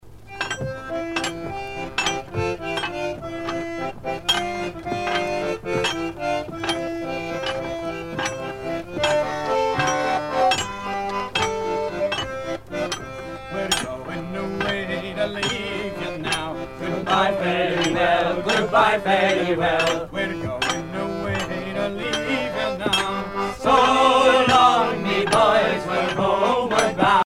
à virer au cabestan
maritimes
Pièce musicale éditée